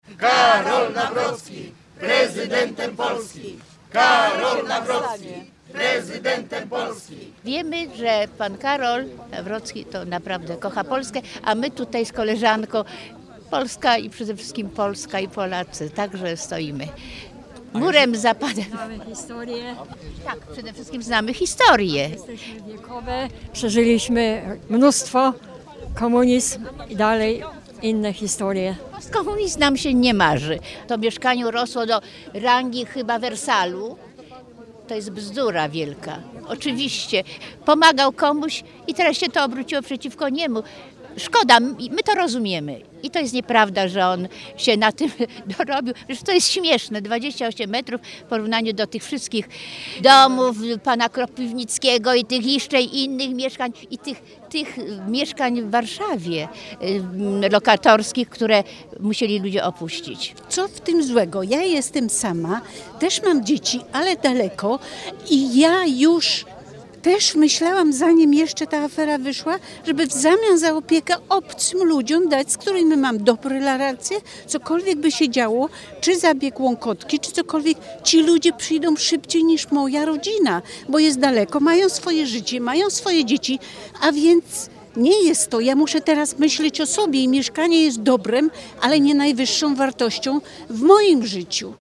Zwolennicy Karola Nawrockiego przekonywali do głosowania na kandydata popieranego przez PiS.
sonda-Karol-Nawrocki.mp3